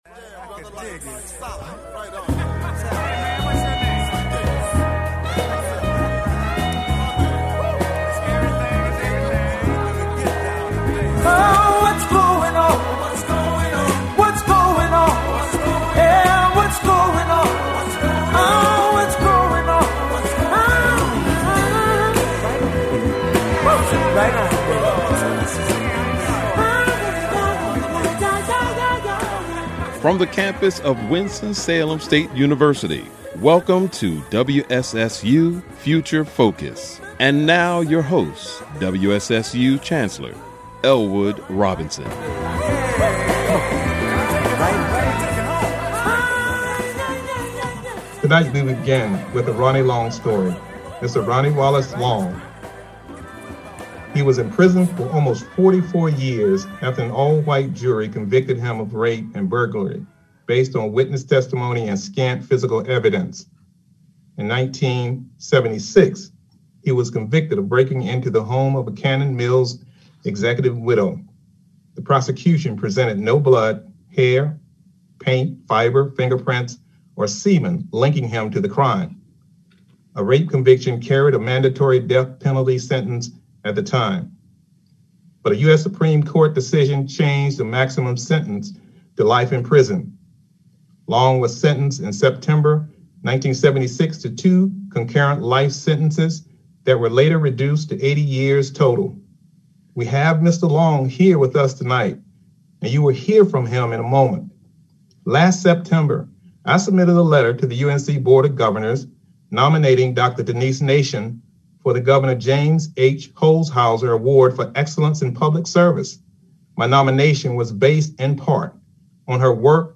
Future Focus is a one-hour public affairs talk show